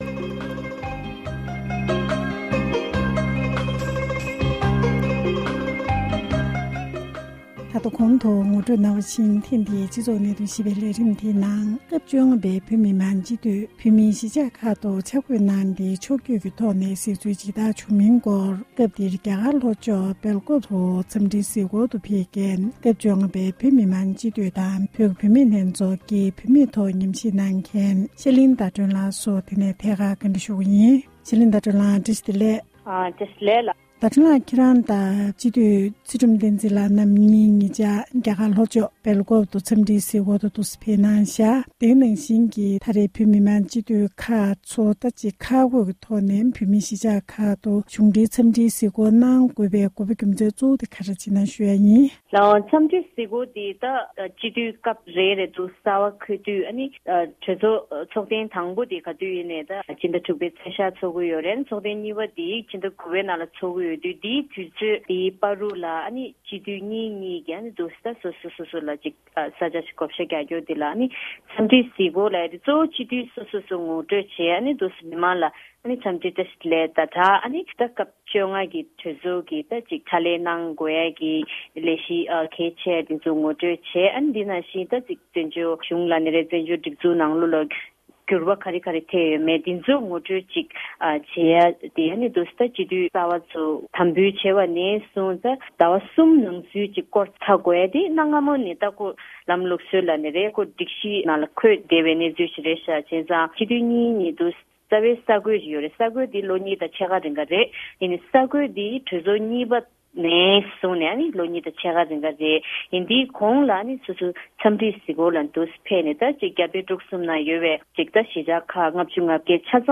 ཞལ་པར་ཐོག་ཐད་ཀར་གནས་འདྲི་ཞུས་པ་ཞིག